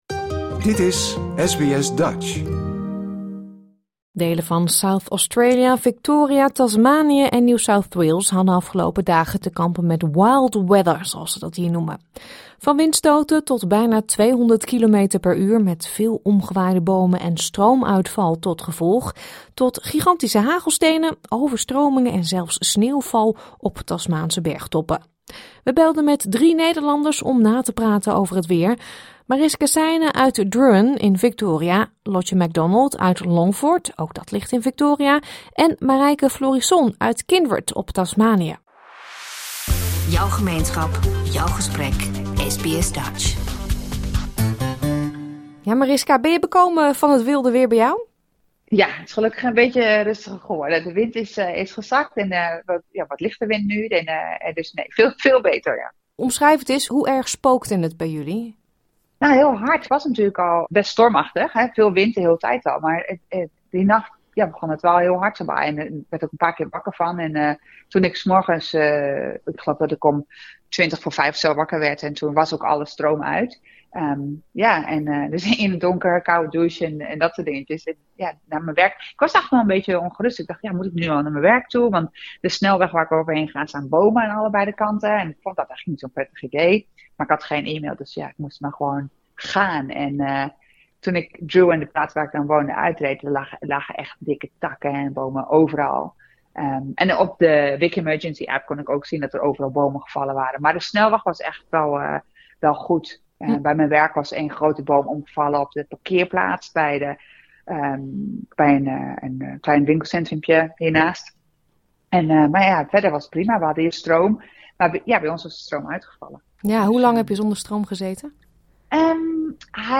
Delen van Zuid-Australië, Victoria, Tasmanië en NSW hadden afgelopen dagen te kampen met 'wild weather'. Van windstoten tot bijna 200 km per uur met veel omgewaaide bomen en stroomuitval tot gevolg, tot gigantische hagelbuien en overstromingen. We belden met drie Nederlanders